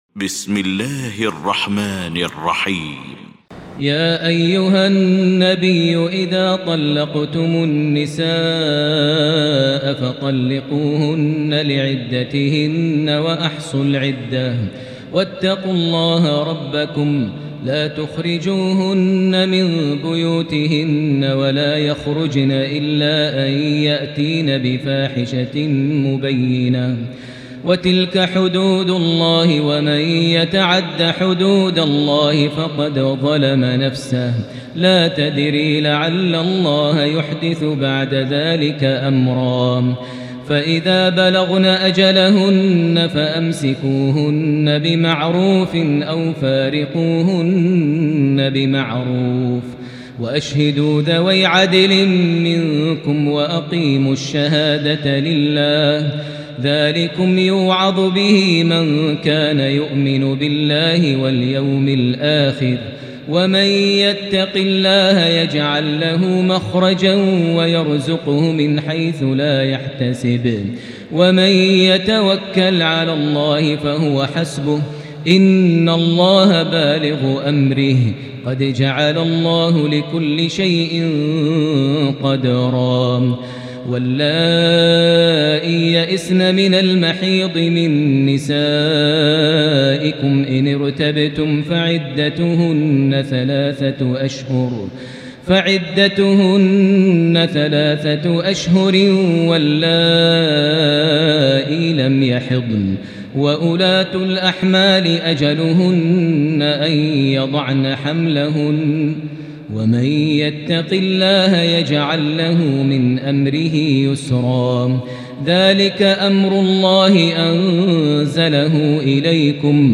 المكان: المسجد الحرام الشيخ: فضيلة الشيخ ماهر المعيقلي فضيلة الشيخ ماهر المعيقلي الطلاق